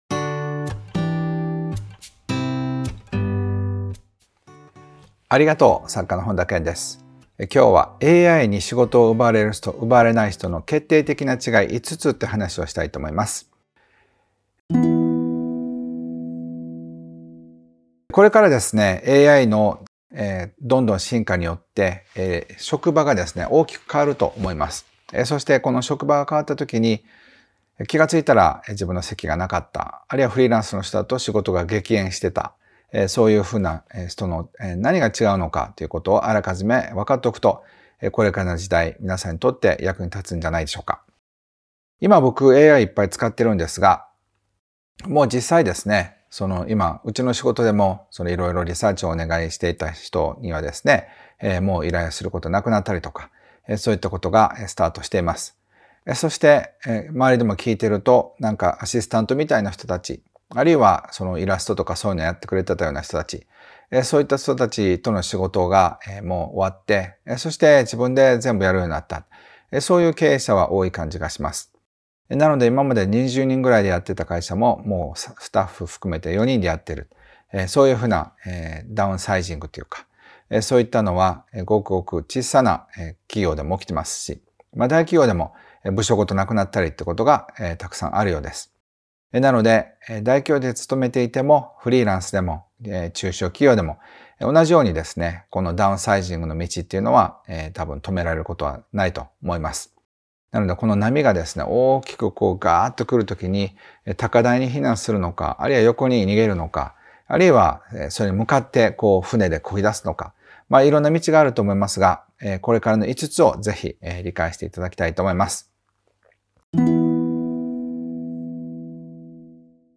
対談